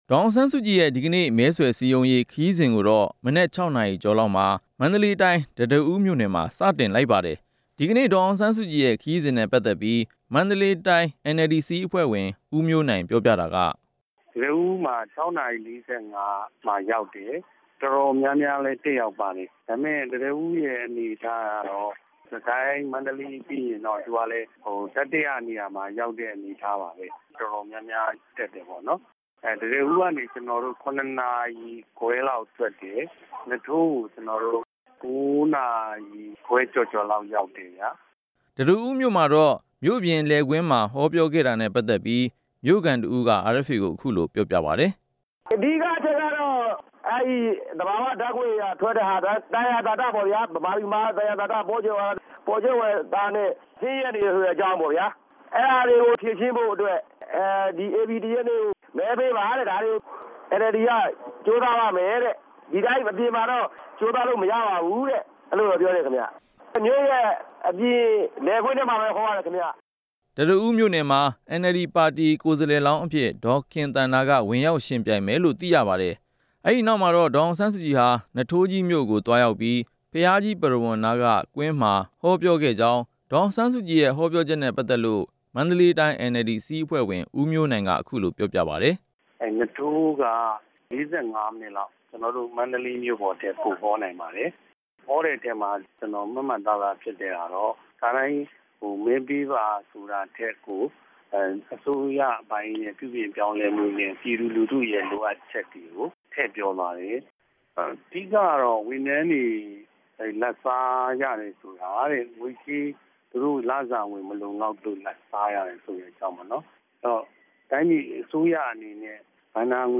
တံတားဦး၊ နွားထိုးကြီး၊ မိထ္ထီလာနဲ့ နေပြည်တော်မှာ ဒေါ်အောင်ဆန်းစုကြည် ဟောပြော